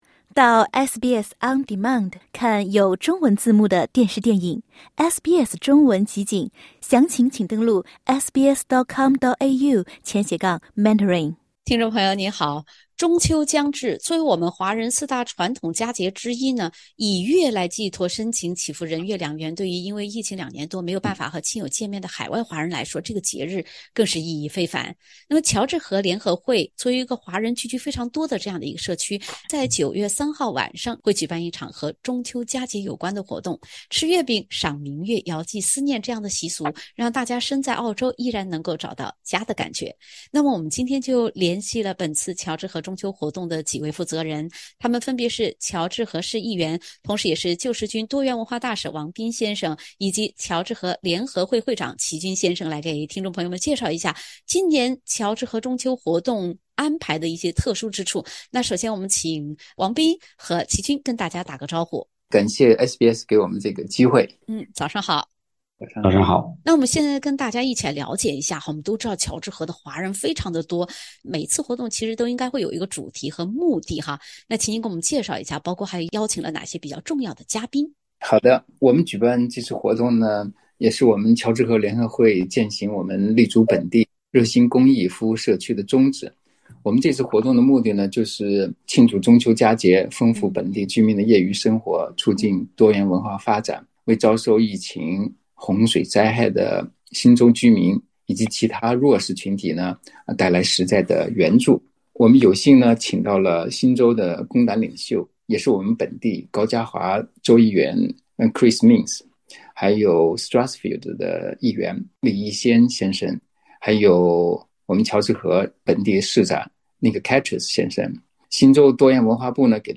乔治河联合会在华人看重的人月两圆之际，依然记挂着此前遭受严重洪灾影响的Hawkesbury。(点击封面图片，收听完整采访）